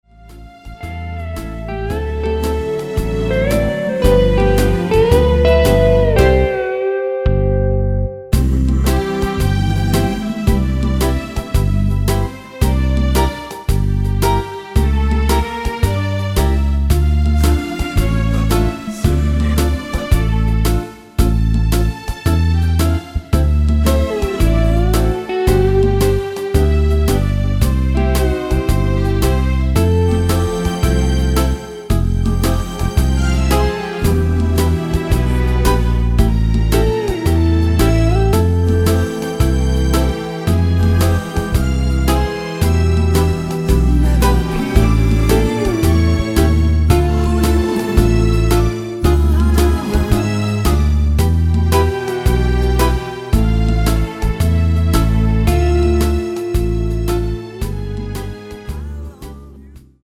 원키 코러스 포함된 MR 입니다.(미리듣기 참조)
앞부분30초, 뒷부분30초씩 편집해서 올려 드리고 있습니다.